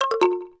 speedwalkoff.wav